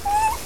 クーコールが聞けます。
屋久島2歳のクーコール